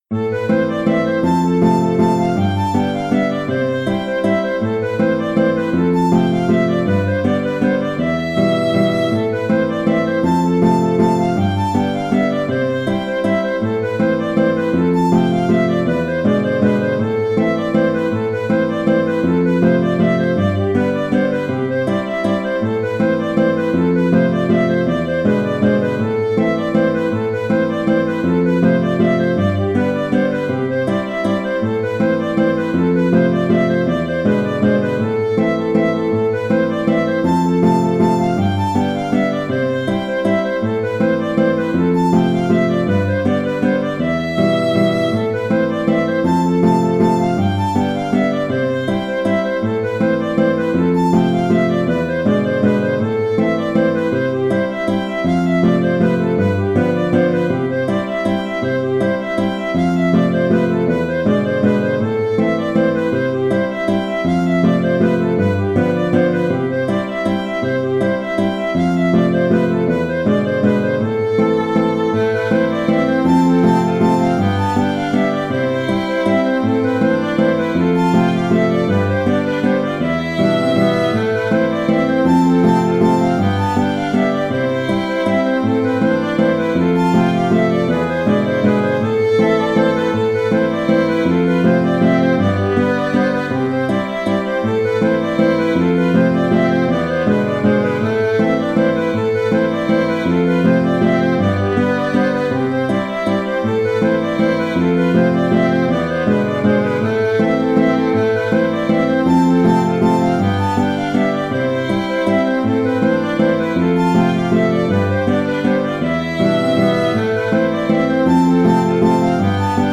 une belle valse bien dynamique